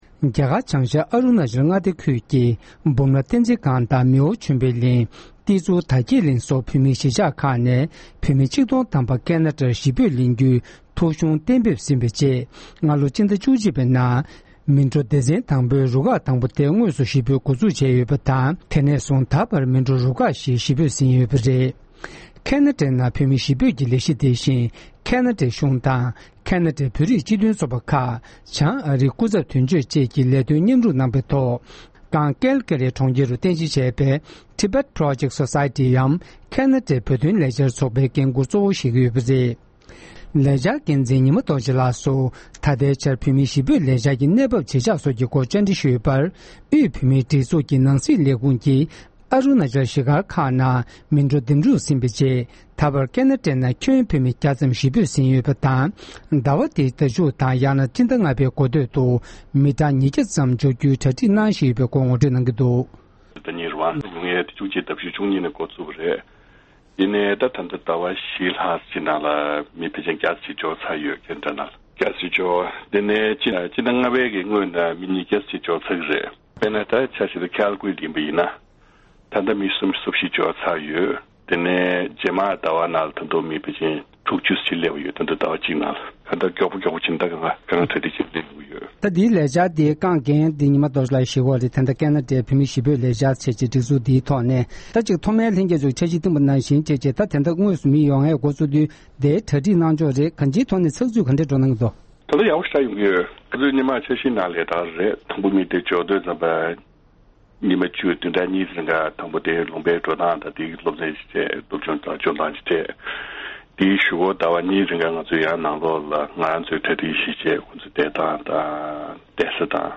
བཅར་འདྲི་ཕྱོགས་བསྒྲིགས་ཞུས་པ་ཞིག་གསན་རོགས་གནང་༎